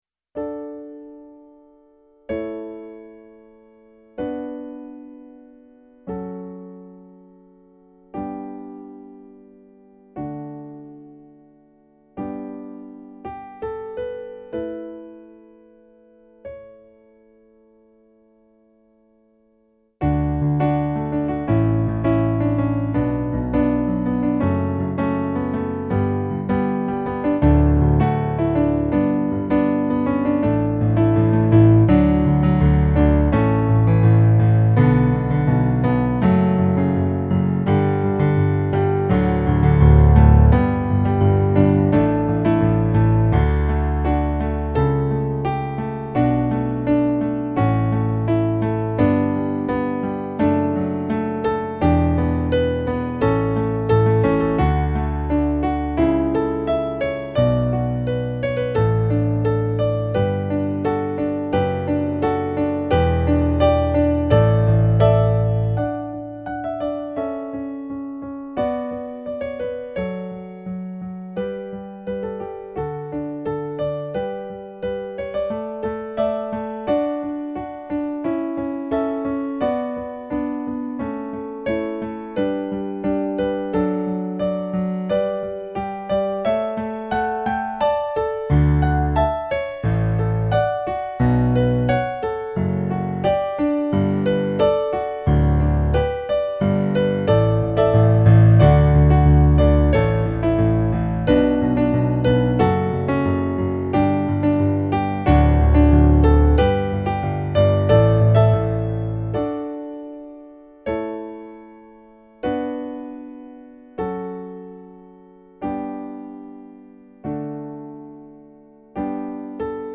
rock remix